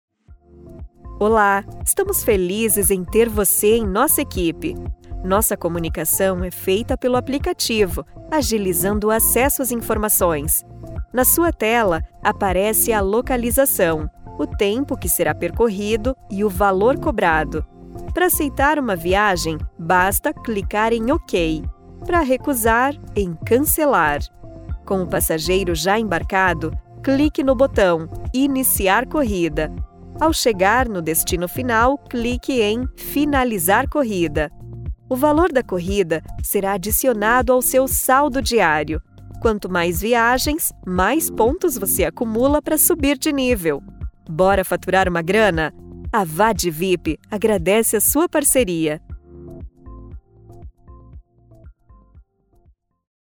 brasilianisch
Sprechprobe: eLearning (Muttersprache):